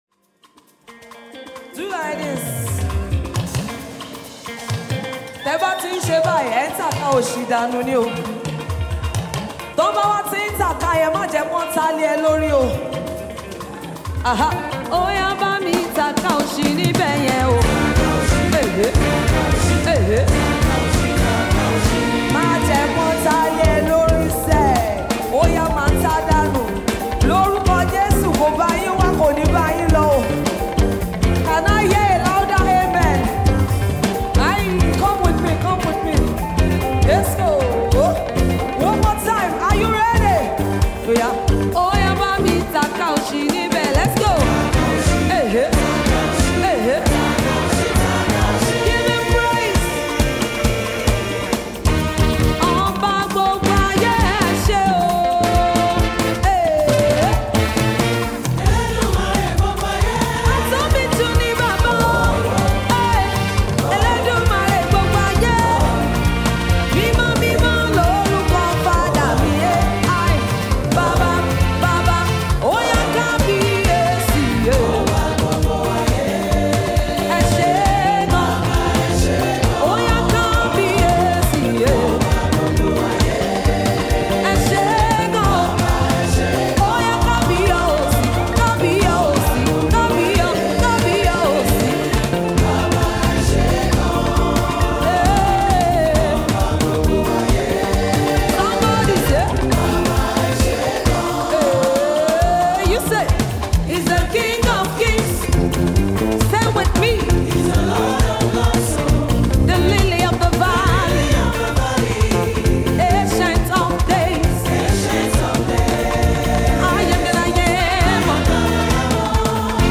the award-winning gospel artist
soulful melodies